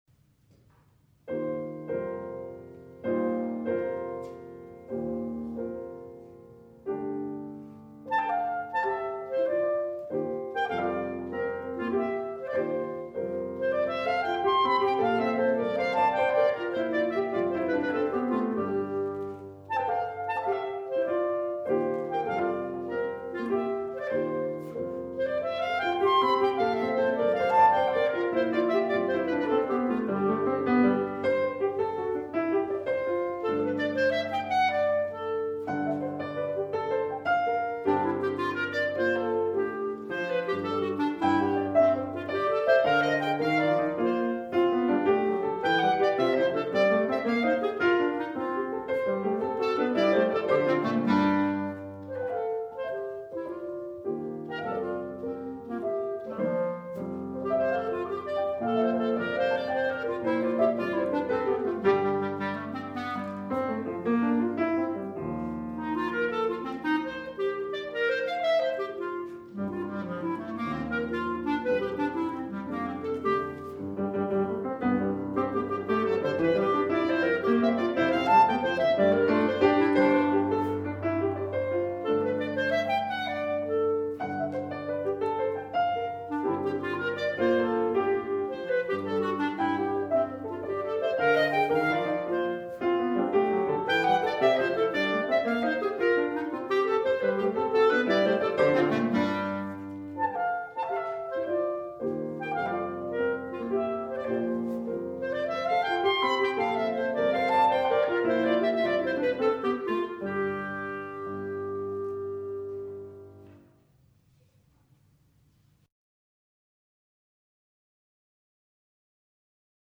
Voicing: Clarinet Solo